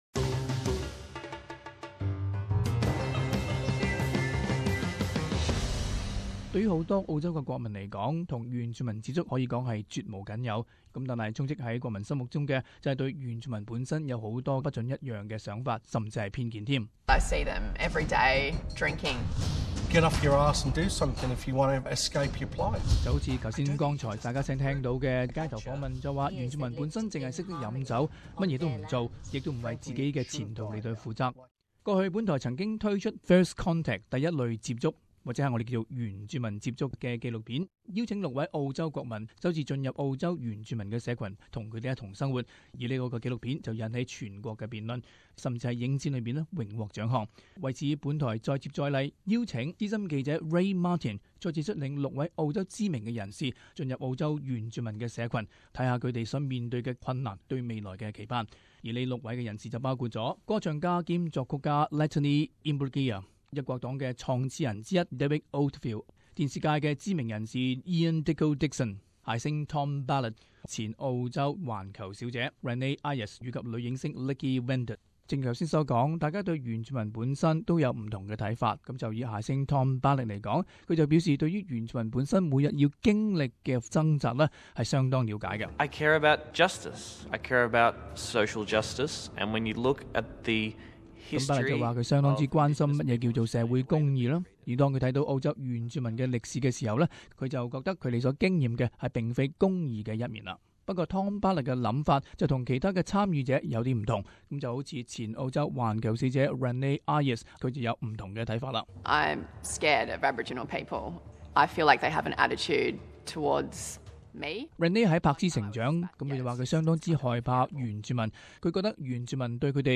【時事報導】原住民接觸的前後體驗